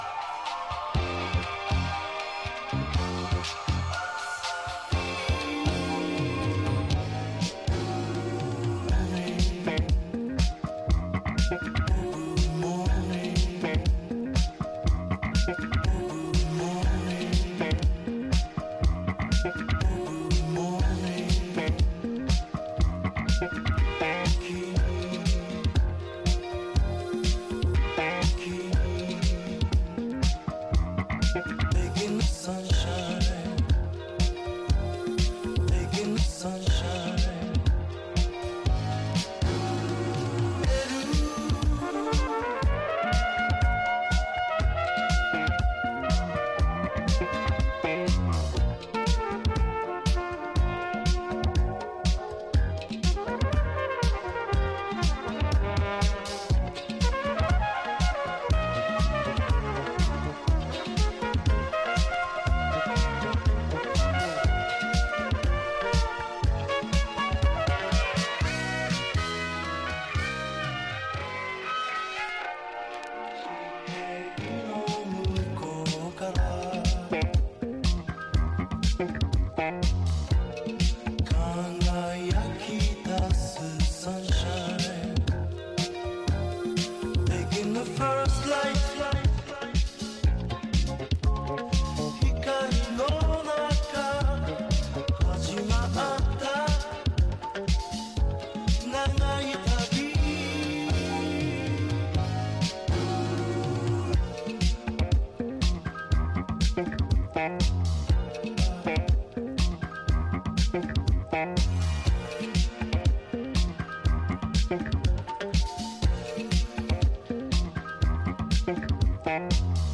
ジャンル(スタイル) DISCO / EDITS